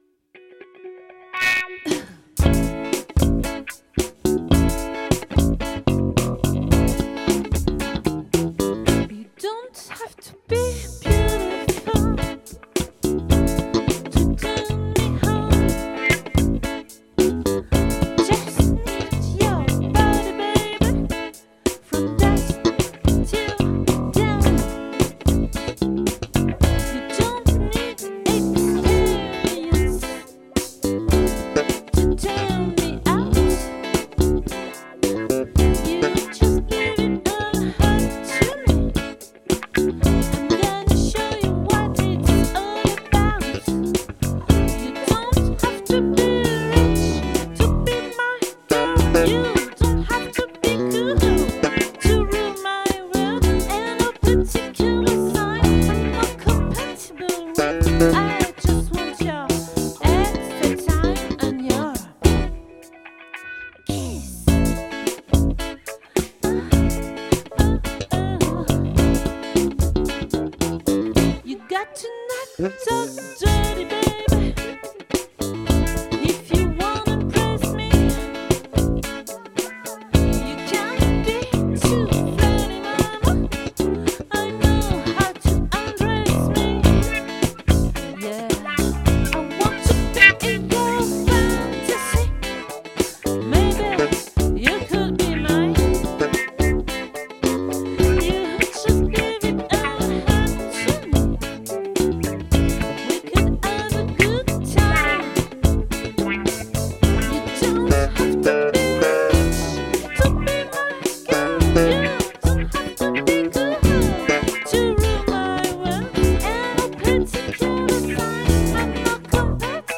🏠 Accueil Repetitions Records_2022_11_09